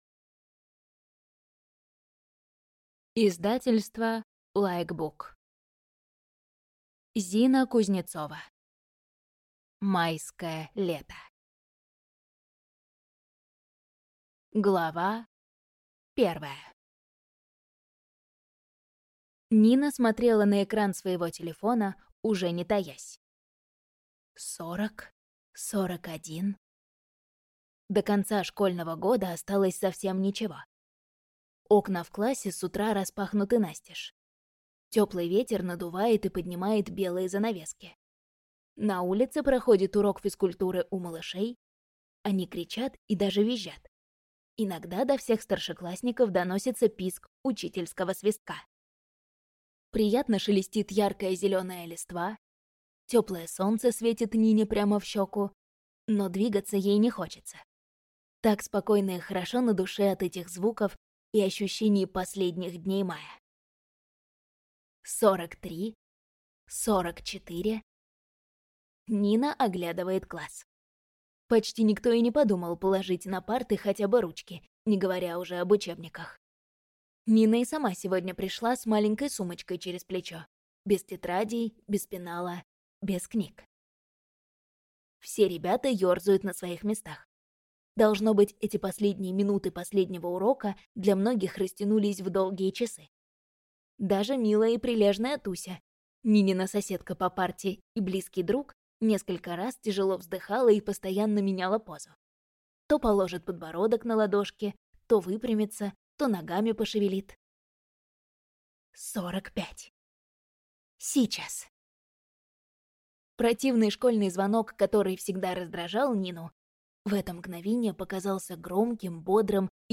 Аудиокнига Майское лето | Библиотека аудиокниг
Прослушать и бесплатно скачать фрагмент аудиокниги